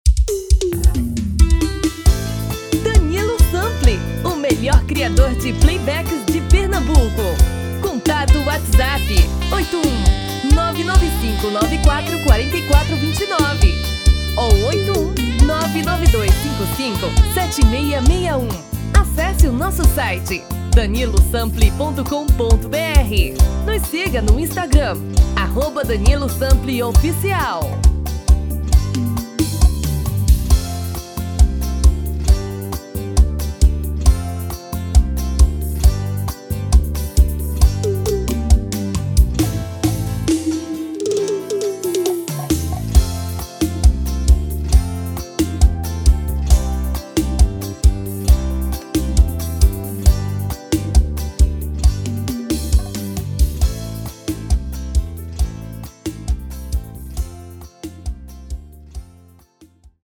COM OPÇÃO DE TONALIDADE ORIGINAL E FEMININO
TOM FEMININO